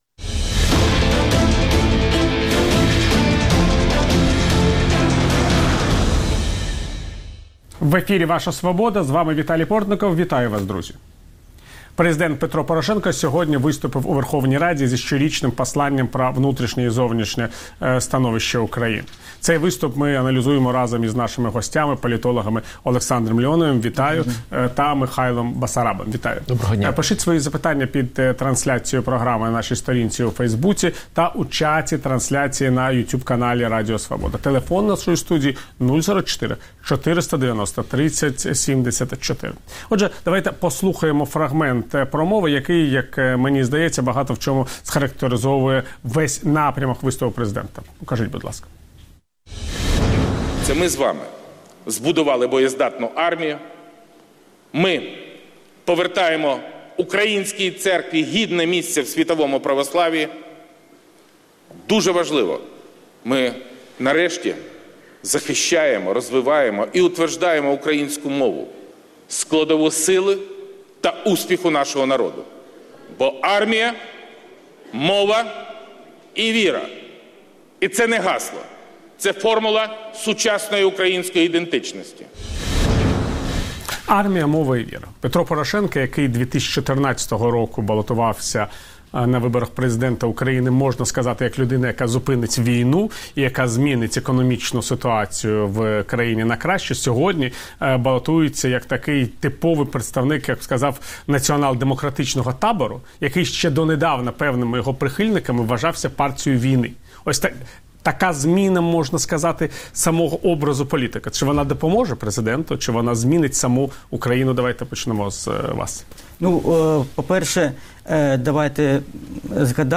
політологи